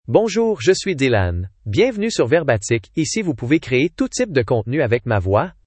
Dylan — Male French (Canada) AI Voice | TTS, Voice Cloning & Video | Verbatik AI
Dylan is a male AI voice for French (Canada).
Voice sample
Male
Dylan delivers clear pronunciation with authentic Canada French intonation, making your content sound professionally produced.